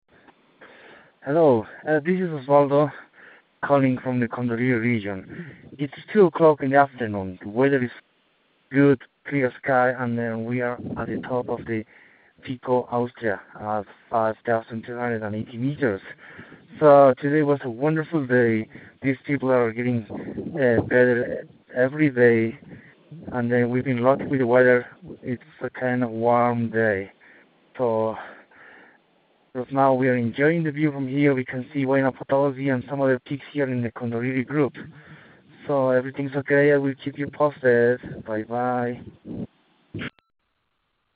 Bolivia Expedition Dispatch